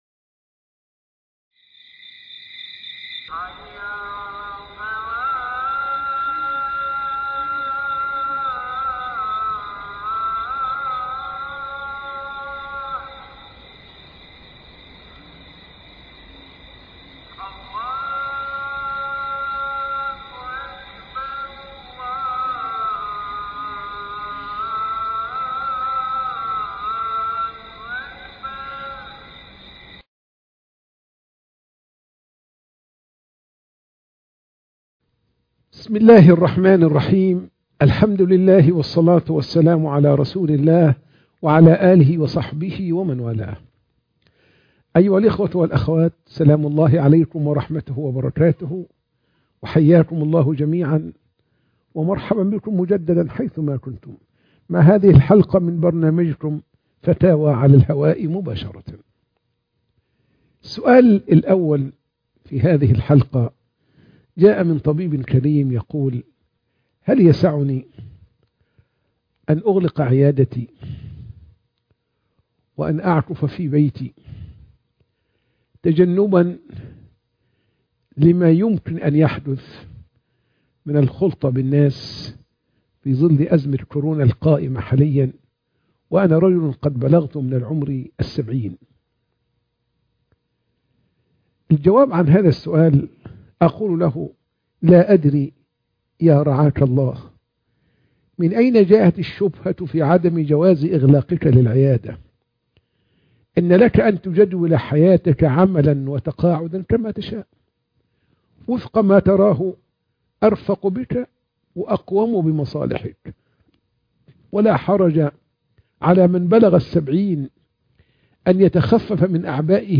فتاوى على الهواء مباشرة (11)